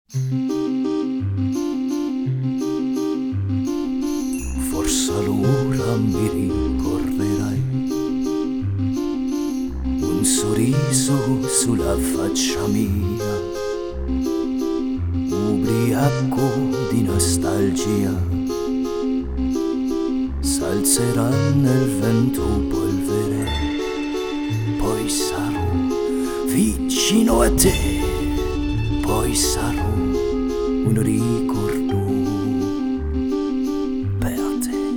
Жанр: Соундтрэки